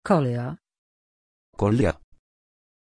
Pronunciation of Kolya
pronunciation-kolya-pl.mp3